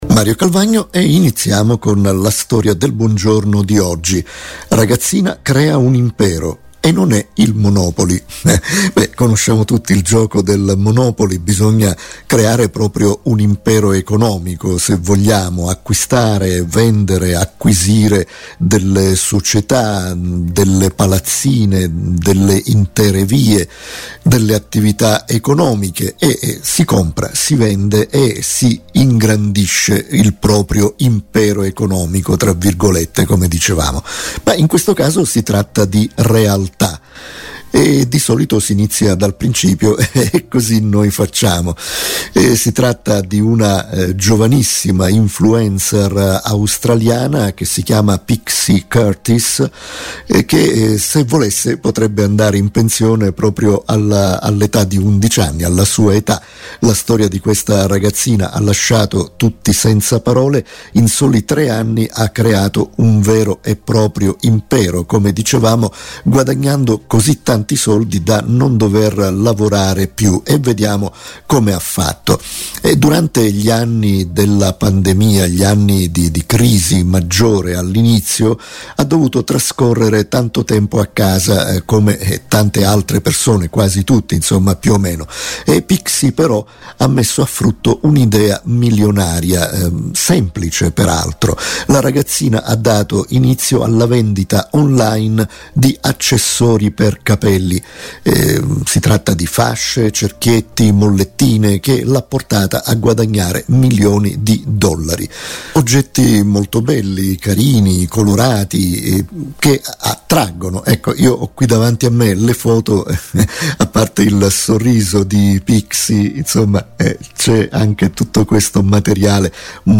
Una storia commentata